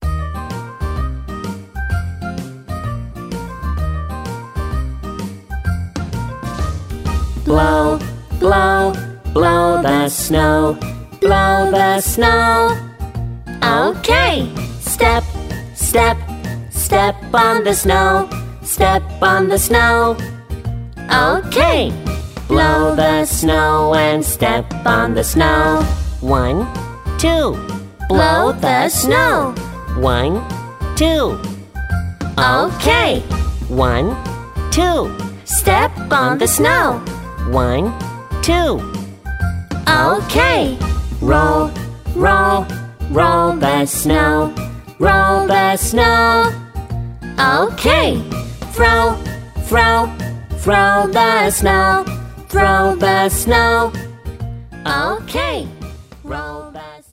남자